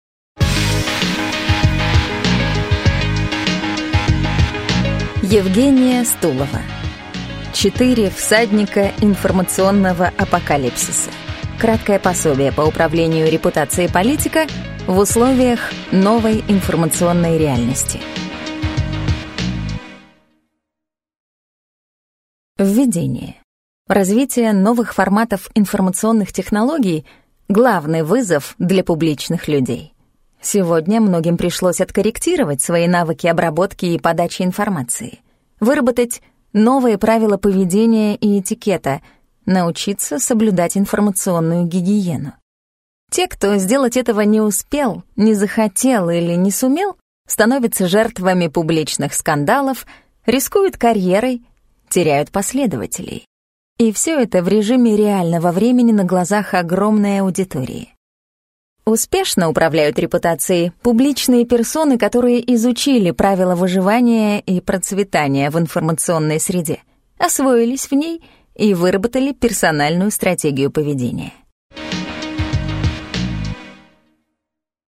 Аудиокнига Четыре всадника информационного апокалипсиса. Краткое пособие по управлению репутацией политика в условиях новой информационной реальности | Библиотека аудиокниг